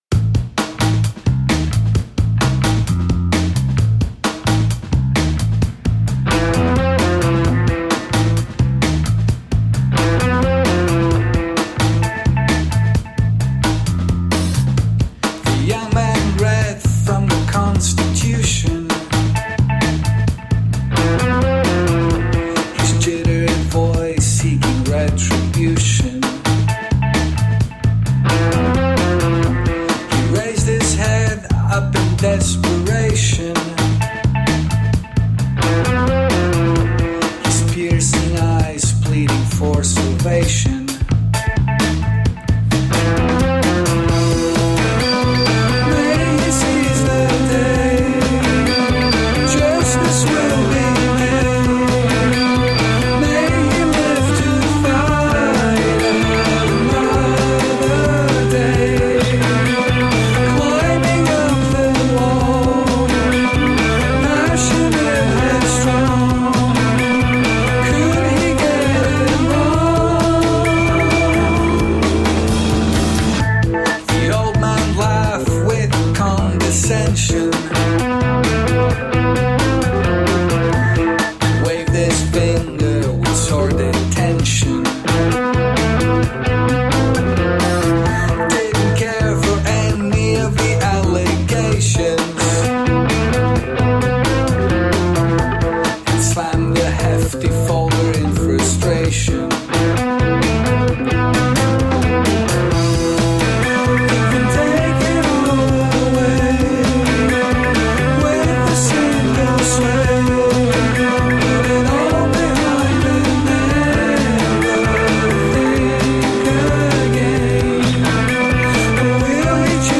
Tagged as: Alt Rock, Pop